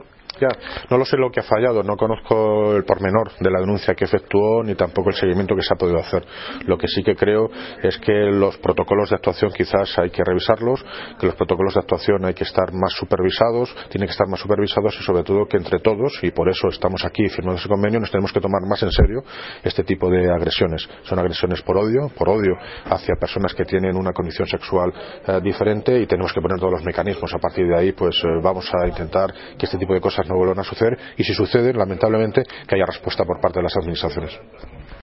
Audio - David Lucas (Alcalde de Móstoles) Sobre Denuncia Agresión LGTB